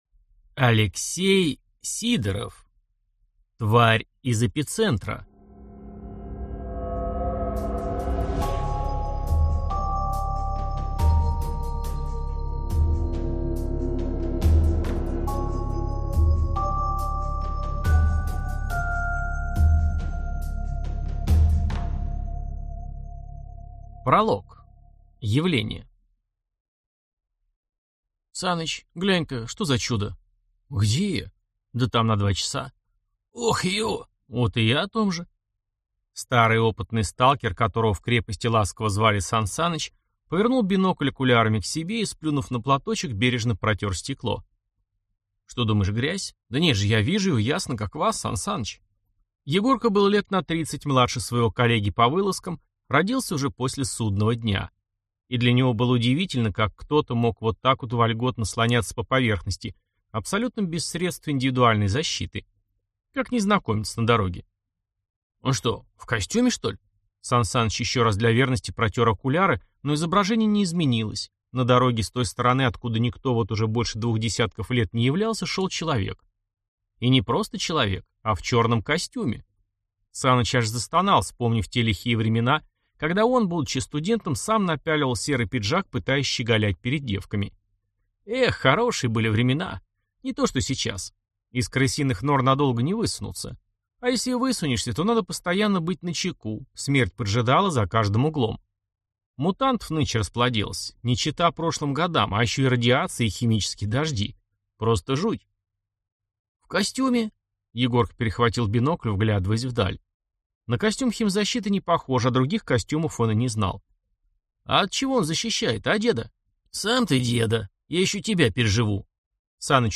Аудиокнига Тварь из эпицентра | Библиотека аудиокниг